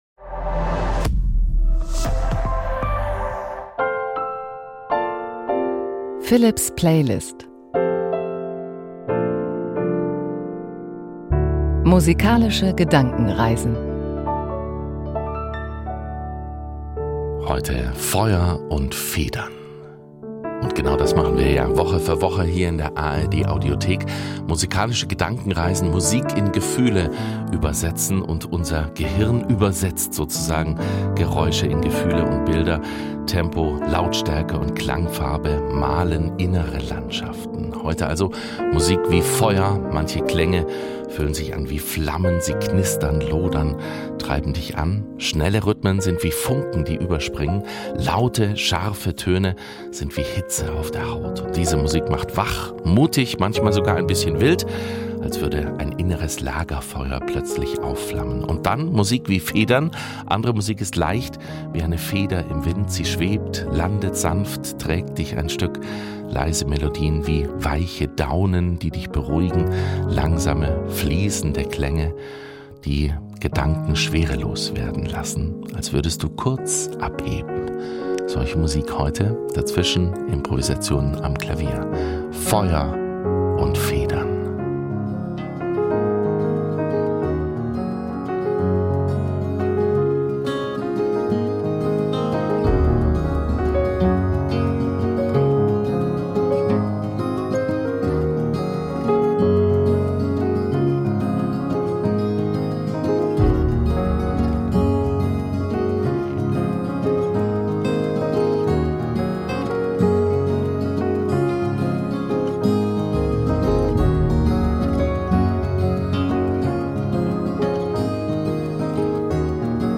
Jede Woche übersetzen wir Musik in Gefühle und Bilder: Diesmal lodernd wie Funken im Wechselspiel mit leichtem Schweben.